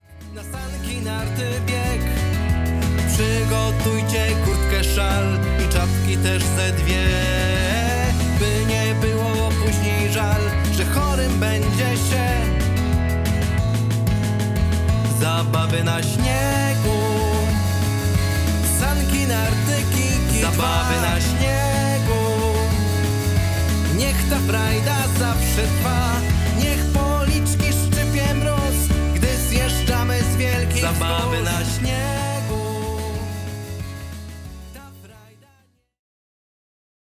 Wesoła, rytmiczna piosenka o zimowych zabawach na śniegu.